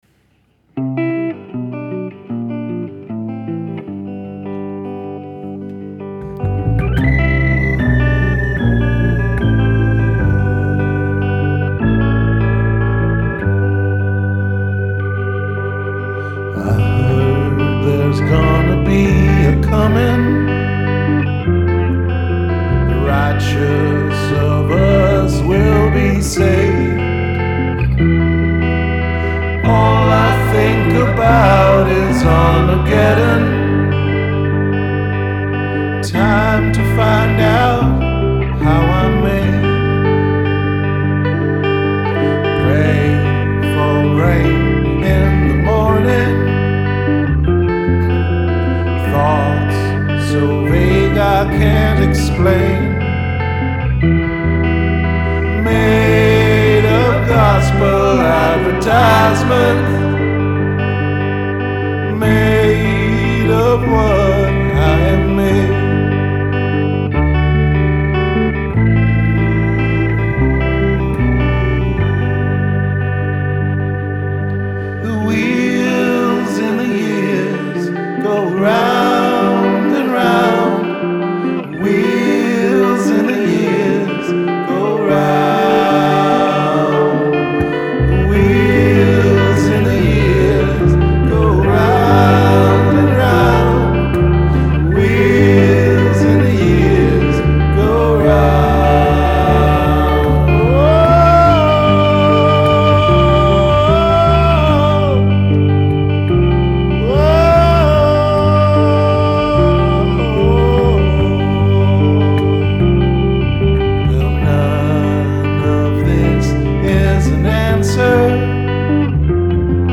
Rehearsals 3.3.2012